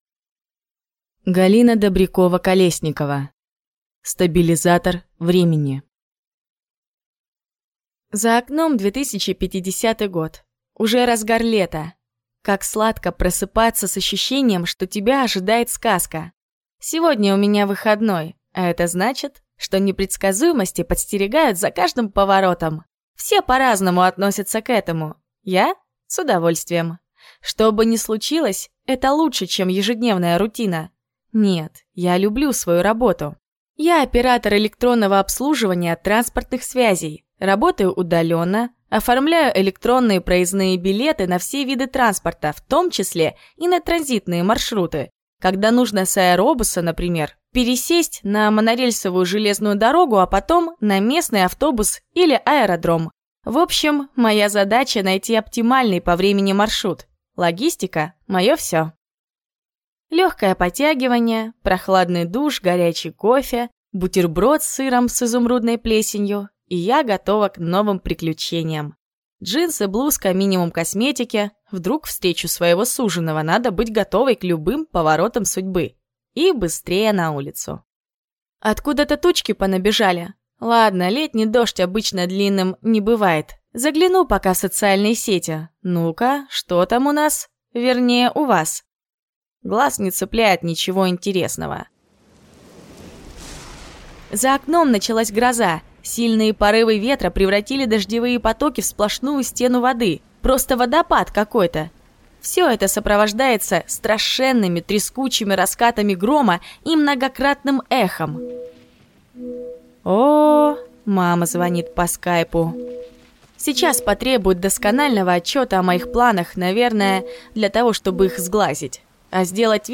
Аудиокнига Стабилизатор времени | Библиотека аудиокниг